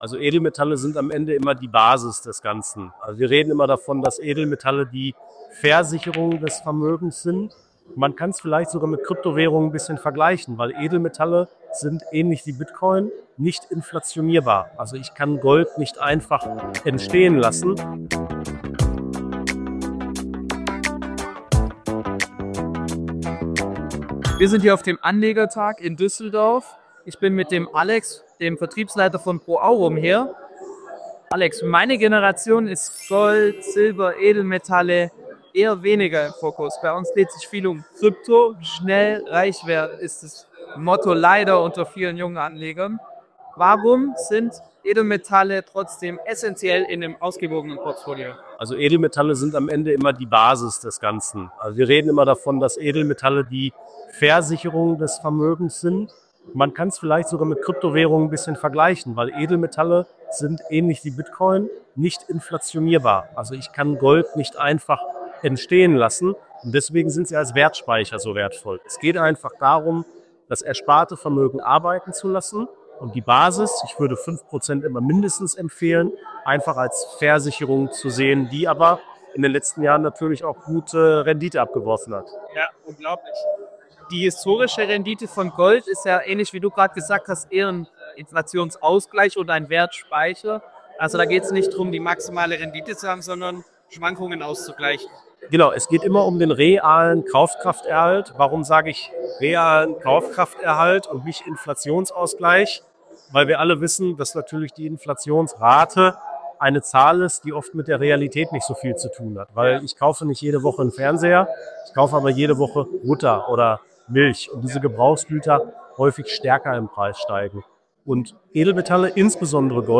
Interview | Anlegertag Düsseldorf 2025 (6/8)